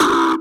VEC3 Percussion 031.wav